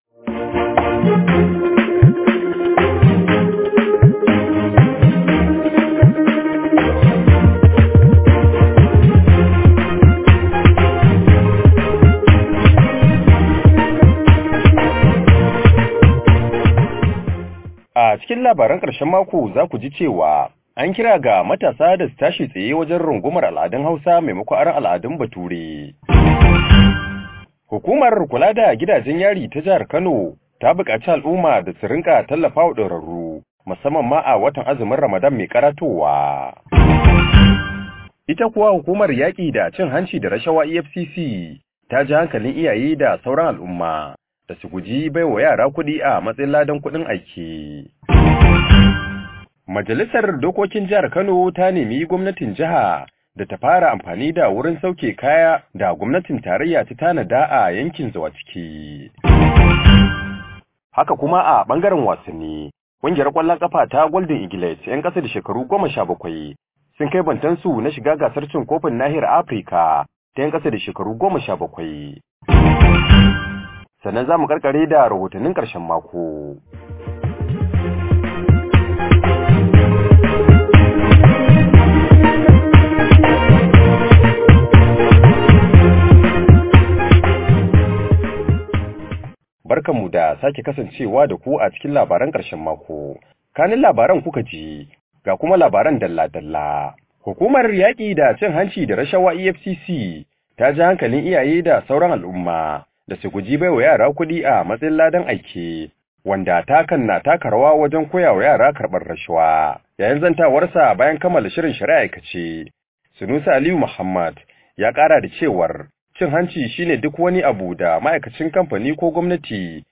Labaran Karshen Mako, shirine da yake kawo muku zafafan labarai da rahotonni na musamman.